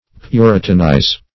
Search Result for " puritanize" : The Collaborative International Dictionary of English v.0.48: Puritanize \Pu"ri*tan*ize\, v. i. [imp.